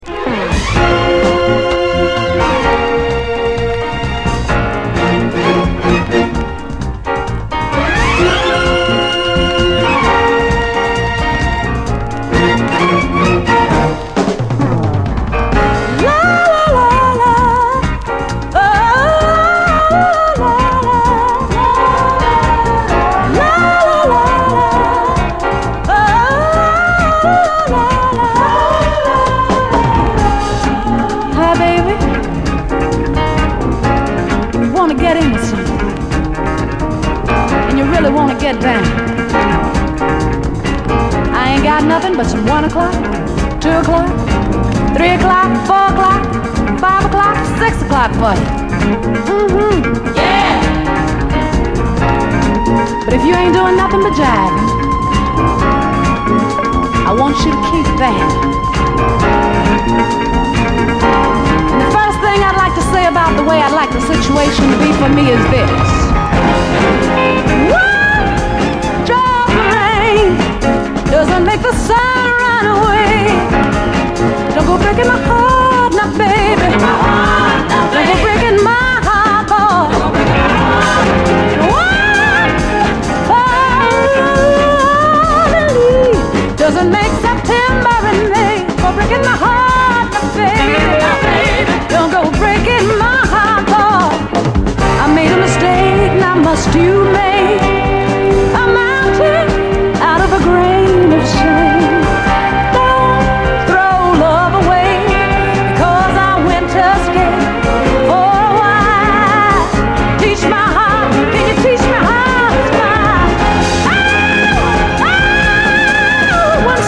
SOUL, 70's～ SOUL, 7INCH
エレガントに疾走するグルーヴィー・ソウル・クラシック！
変幻自在な展開、ファンキーなのに流れるような疾走感が最高です！